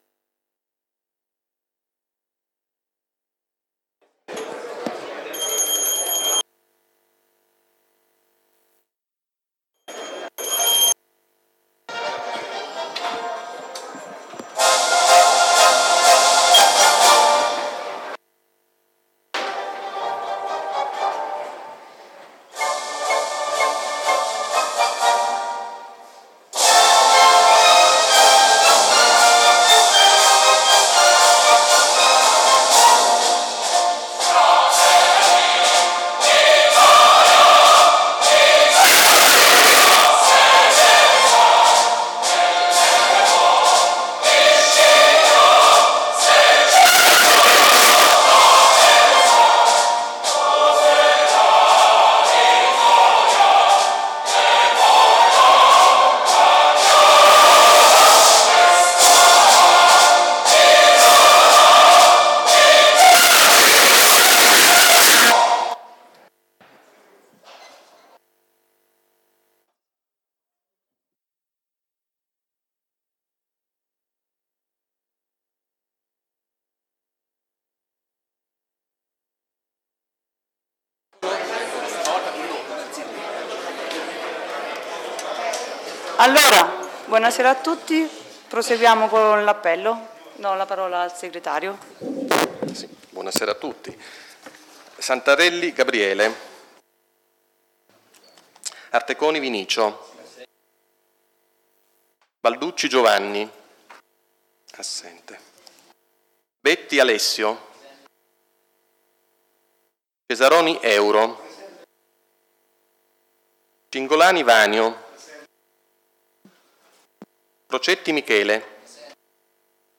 Il Consiglio Comunale è convocato per il giorno 11 luglio 2017 alle ore 18:00 presso la sede dell'Unione Montana dell'Esino-Frasassi con il seguente Ordine del Giorno: